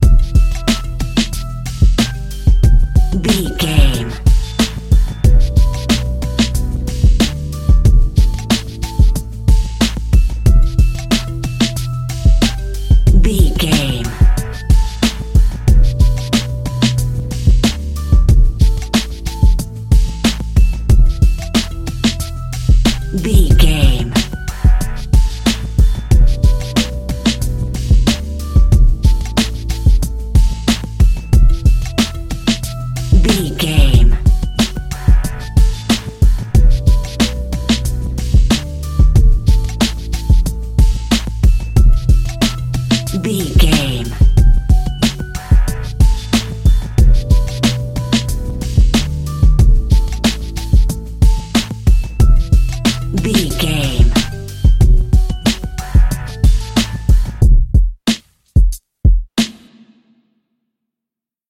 Aeolian/Minor
drum machine
synthesiser
electric piano
hip hop
Funk
neo soul
acid jazz
energetic
bouncy
funky